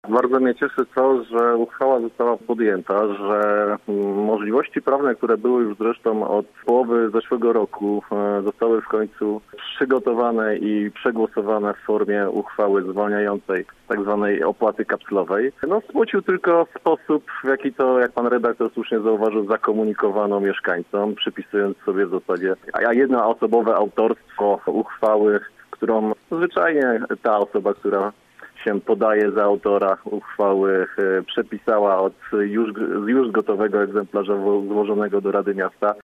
O to pytał gość Rozmowy po 9: